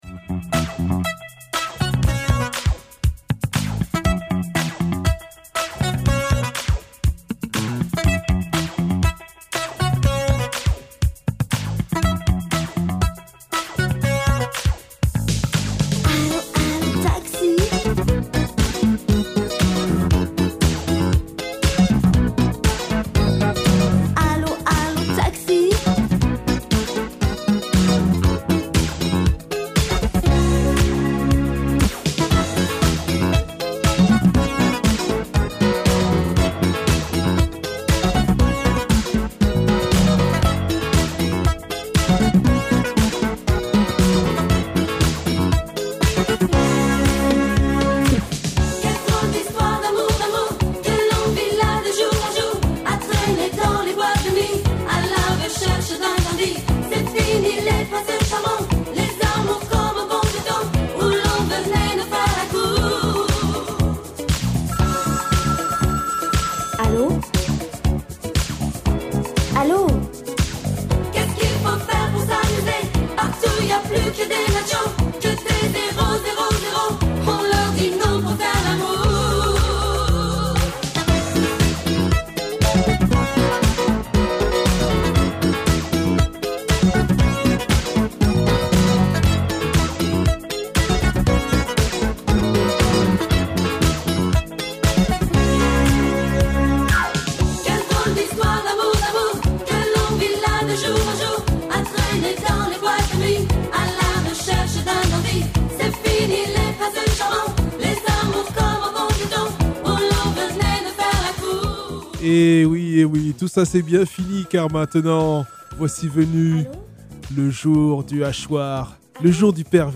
en concert au Canadian Café le 16 février dernier…